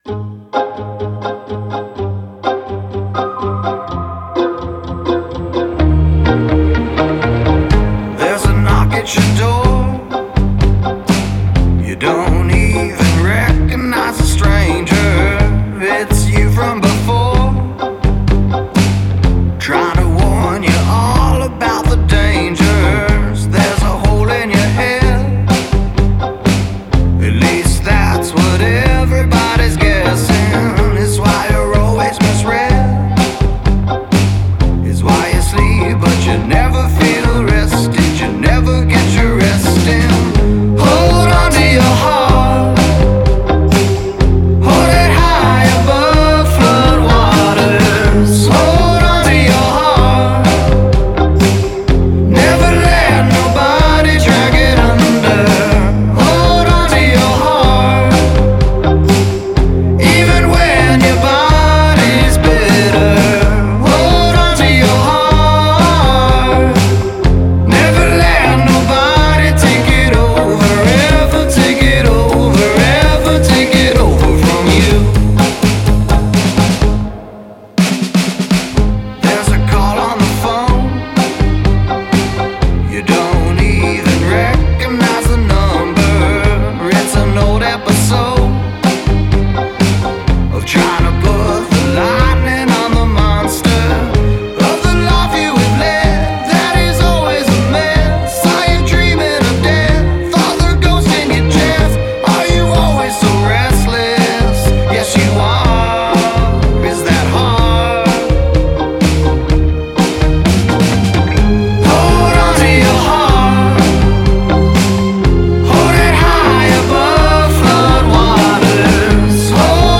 tone down their quirky
nightmare cabaret tendencies